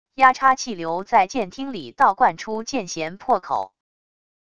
压差气流在舰厅里倒灌出舰舷破口wav音频